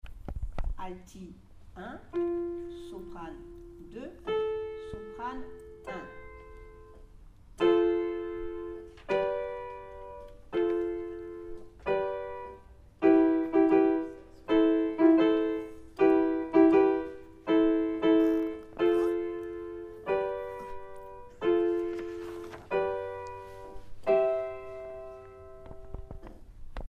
alto1 sop1 2 mes57